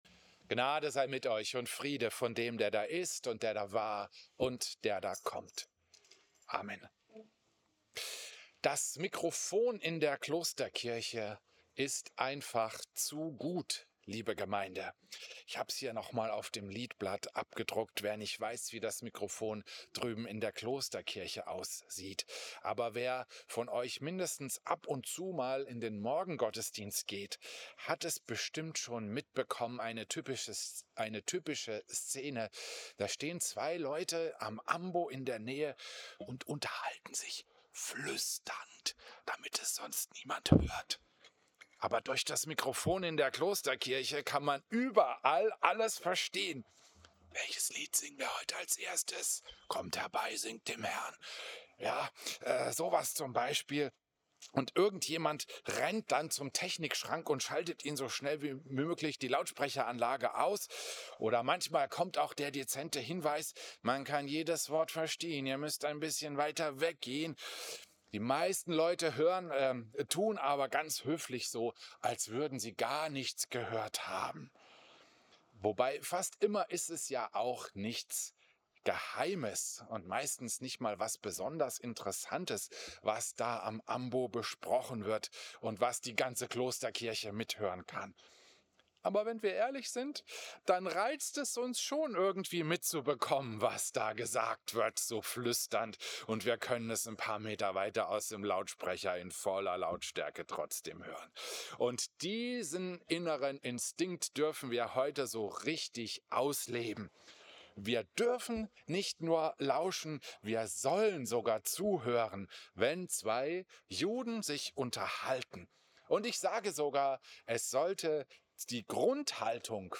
Predigt
Gitarre
Gesang
Christus-Pavillon Volkenroda, 25.